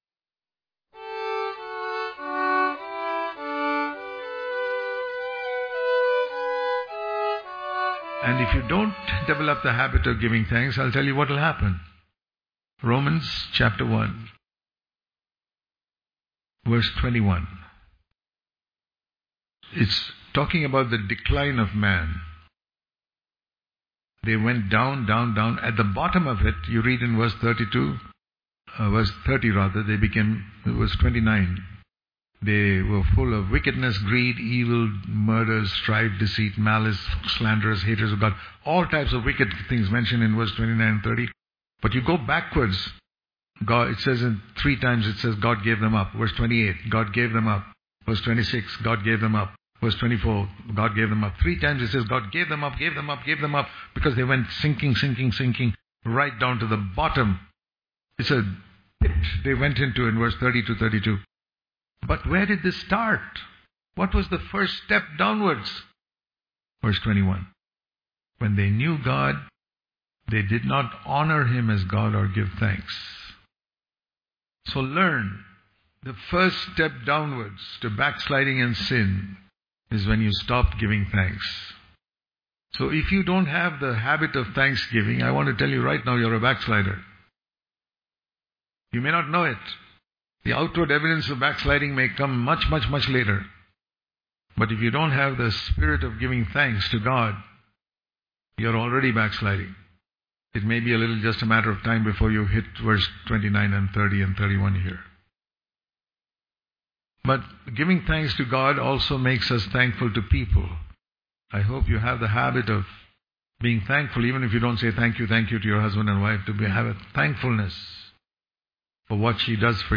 Daily Devotion